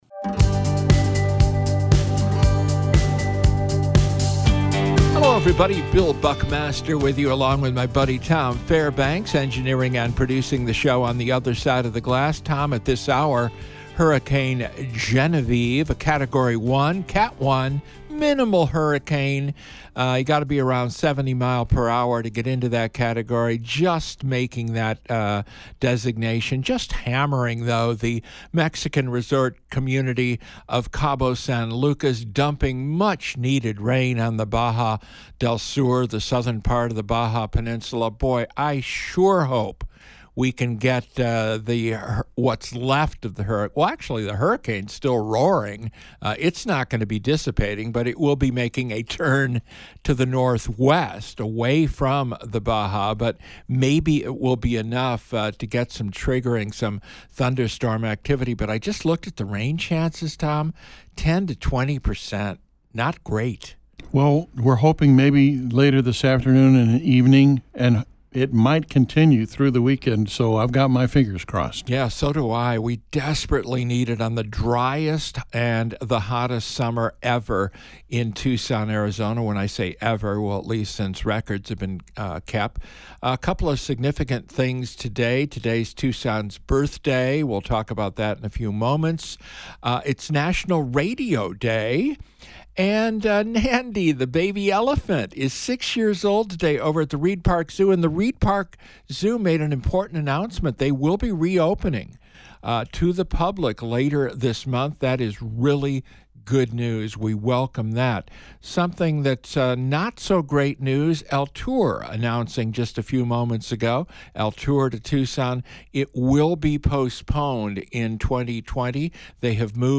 A newsmaker interview